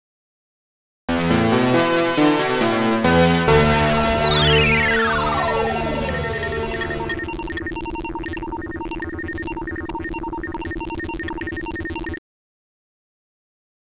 Lite Cover over Korg Minilogue